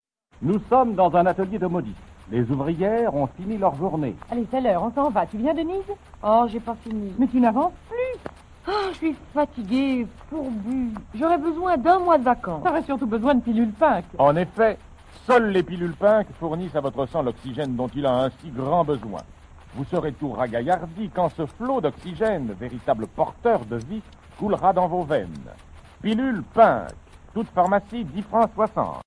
Quelques très imaginatives publicités radiophoniques des pilules Pink :